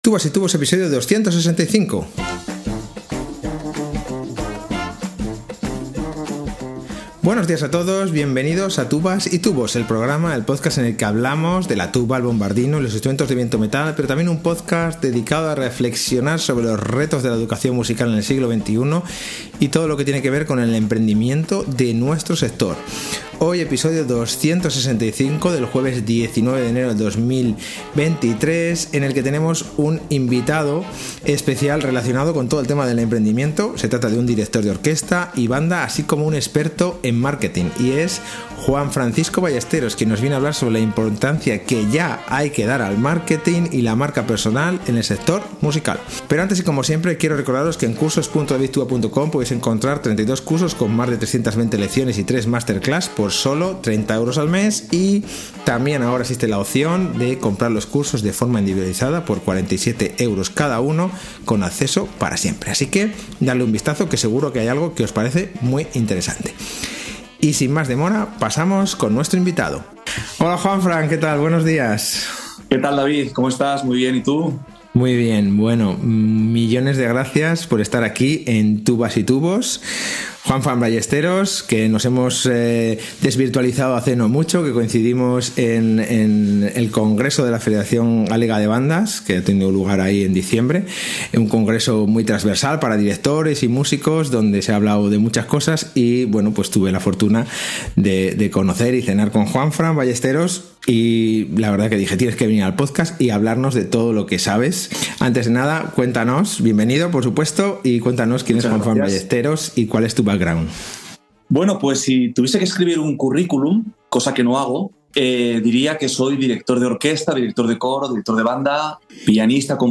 Entrevisa